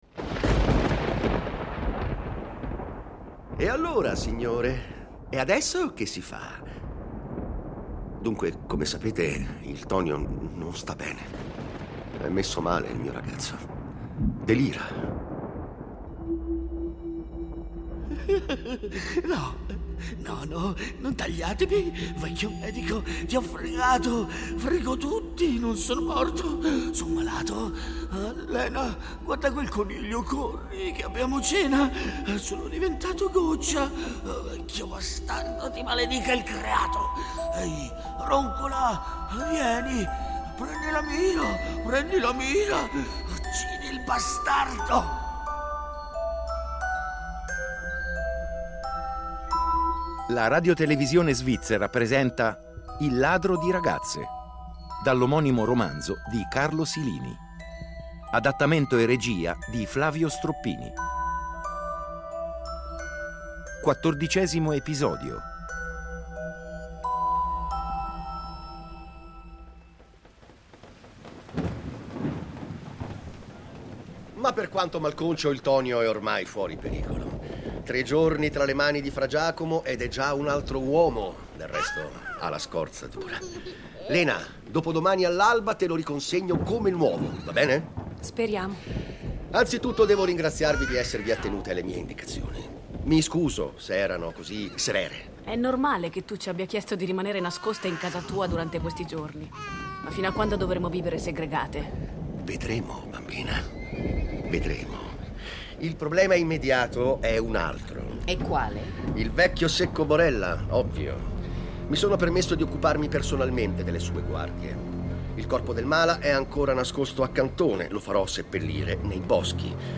Un “film per le orecchie” che in un crescendo di continui colpi di scena, tradimenti, omicidi e con un sound design d’eccellenza, racconta dell’epica resistenza degli ultimi e della loro vendetta.